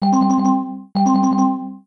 announcement.ogg